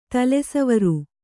♪ tale savaru